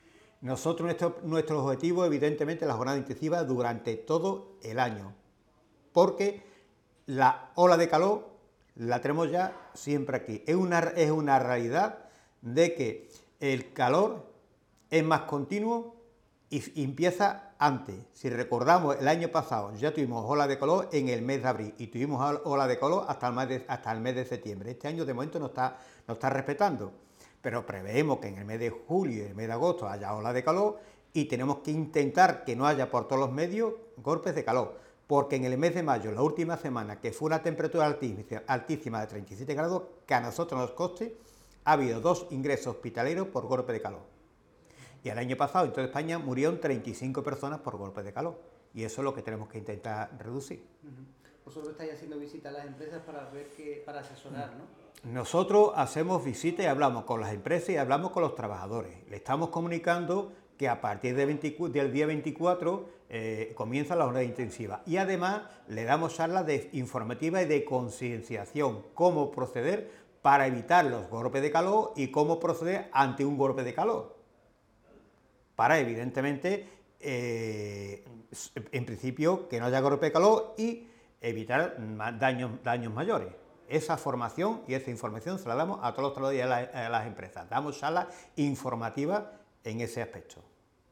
Audio de valoración